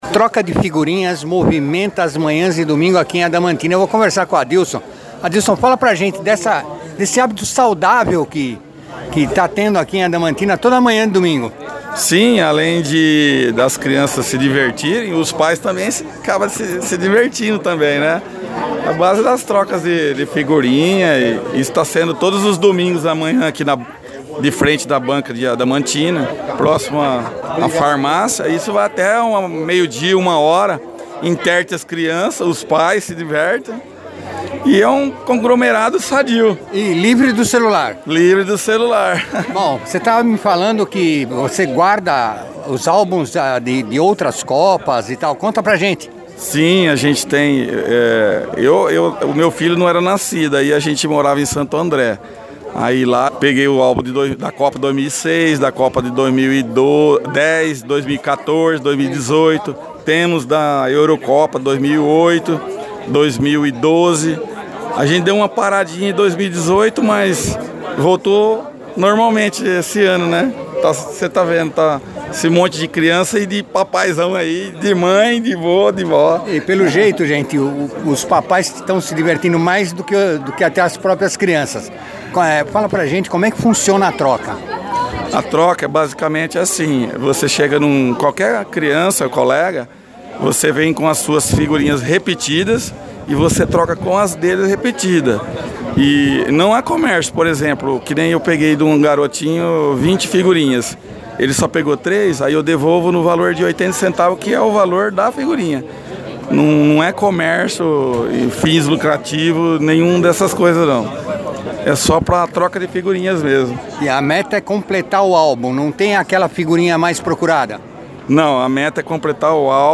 Nossa reportagem entrevistou alguns pais, todos afirmaram estar se divertindo tanto quanto seus filhos, falando inclusive do benefício de estar momentaneamente oferecendo uma opção ao celular e aos jogos eletrônicos.
Reportagem-sobre-troca-de-figurinhas.mp3